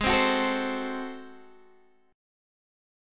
Ab7b9 chord